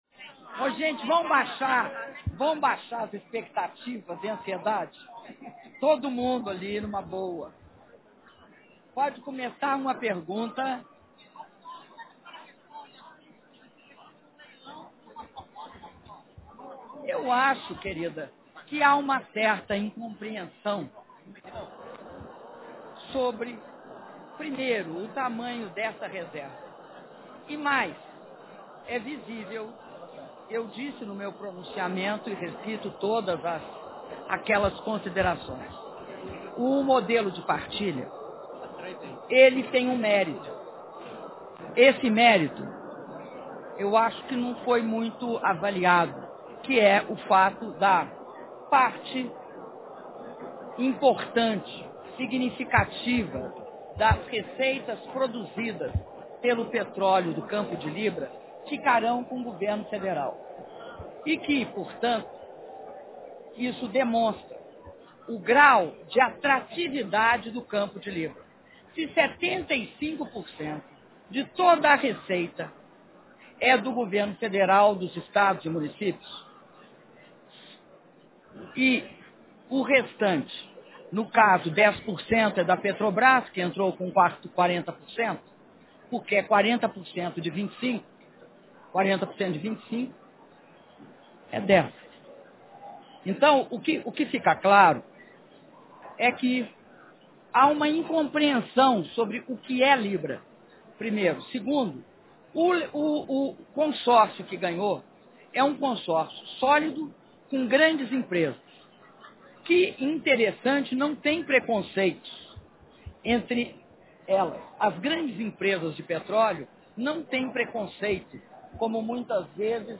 Entrevista coletiva concedida pela Presidenta Dilma Rousseff após cerimônia de sanção da Lei que institui o Programa Mais Médicos - Brasília/DF